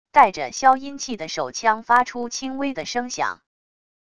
带着消音器的手枪发出轻微的声响wav音频